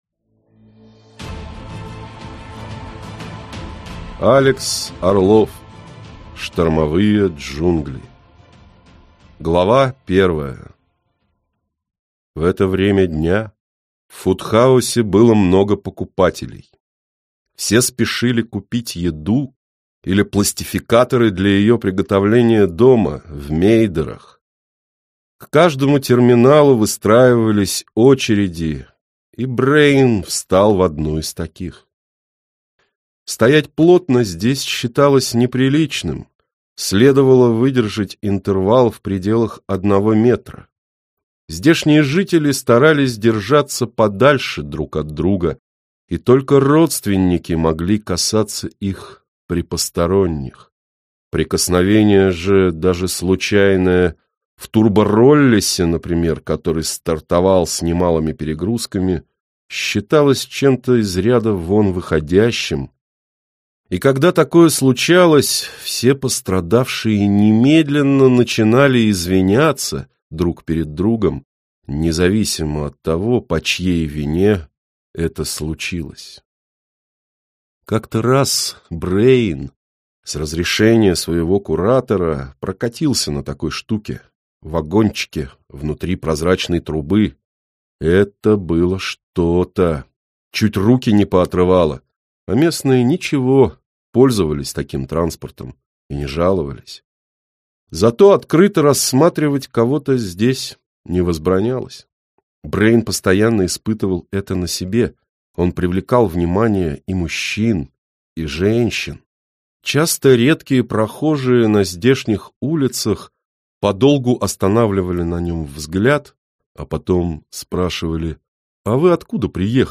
Аудиокнига Штормовые джунгли | Библиотека аудиокниг